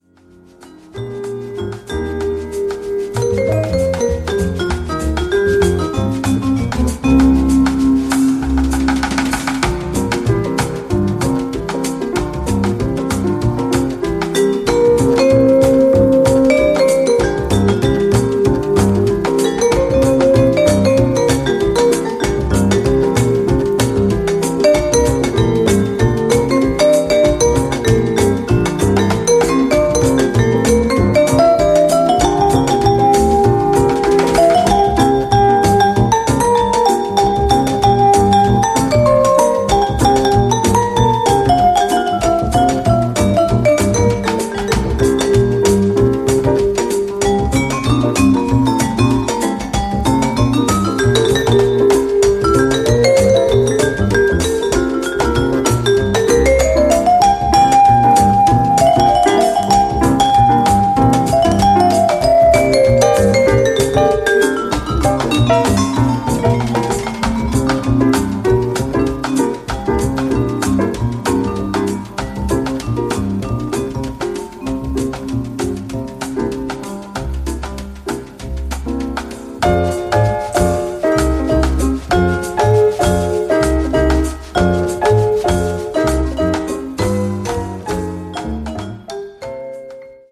Cuban jams!
Latin jazz